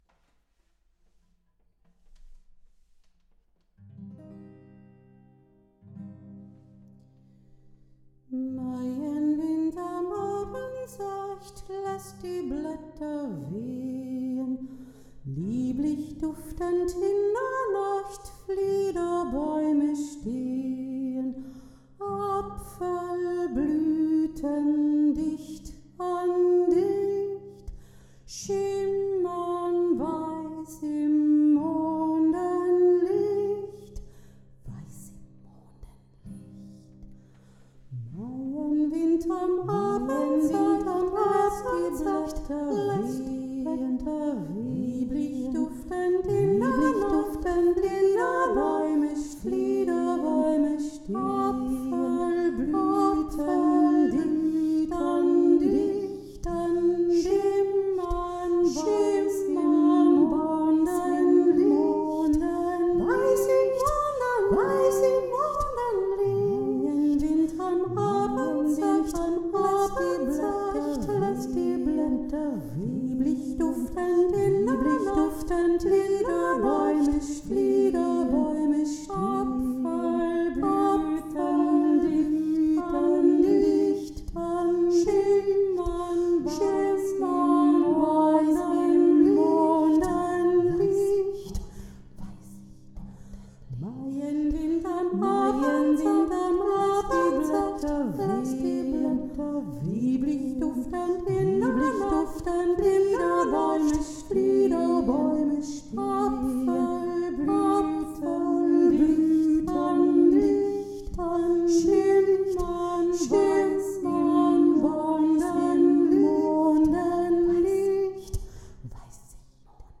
Maienwind KANON
maienwind-kanon-text.mp3